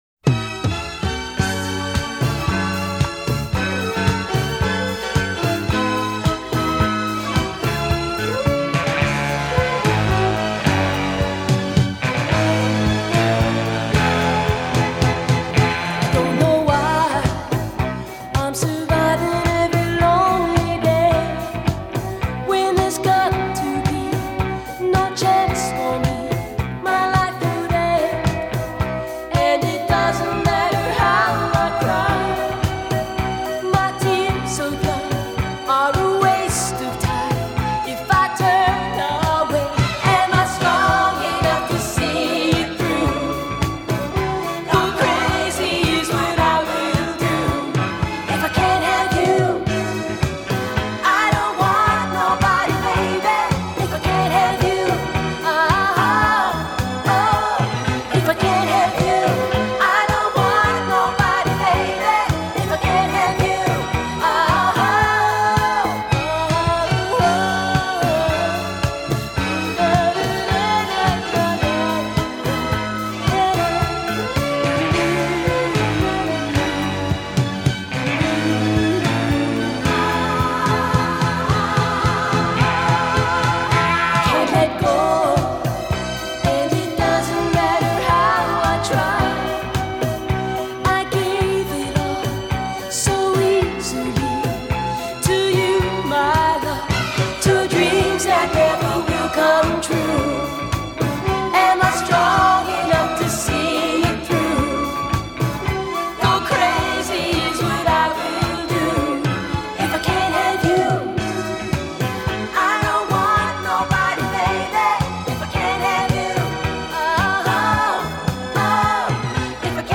to have a shot at a more-uptempo disco number.